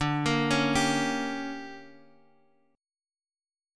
D Major 7th Suspended 4th Sharp 5th